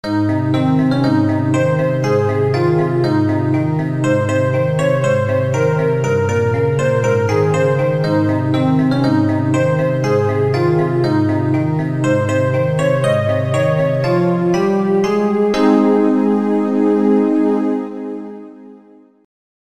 ＊実録（テープ放送）と音程が違いますがご了承ください。